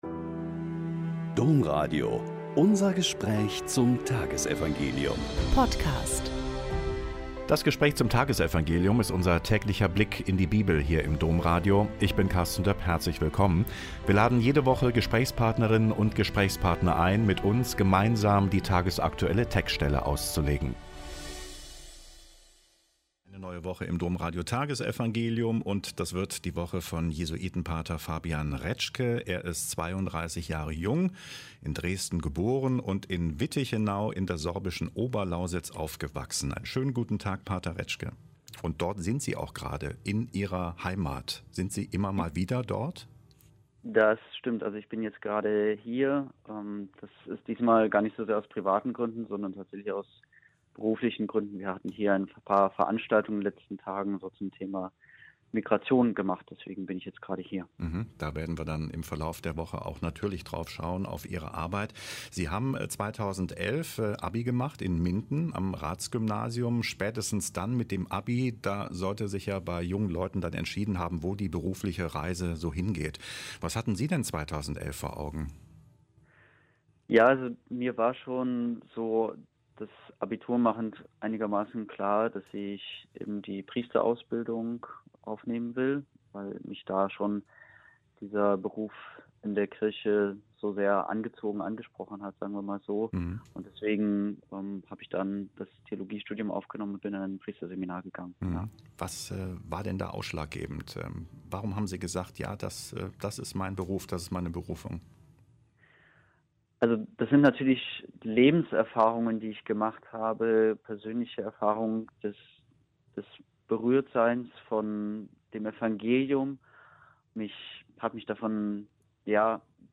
Joh 1,47-51 - Gespräch